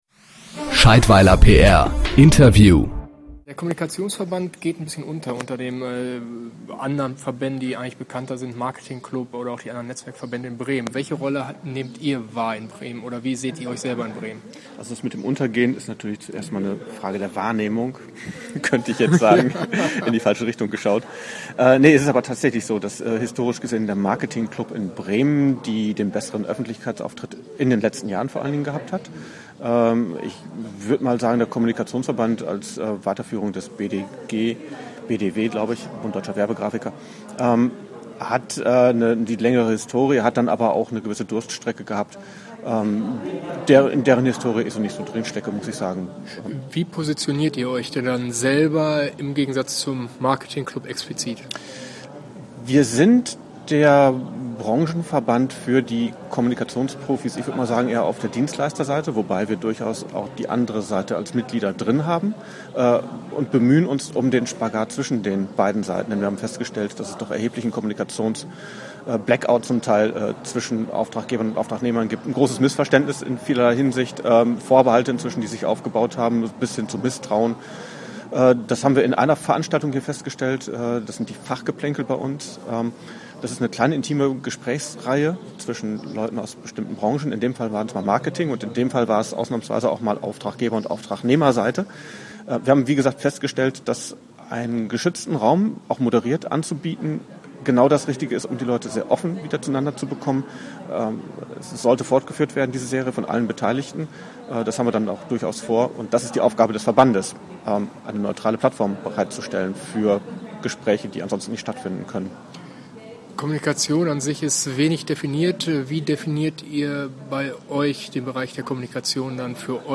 PR-Podcast: Der Kommunikationsverband Bremen, Interview